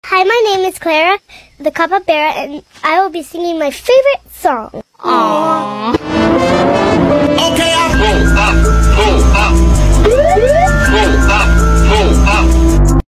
PULL UP PULL UP WHOOP Sound Effects Free Download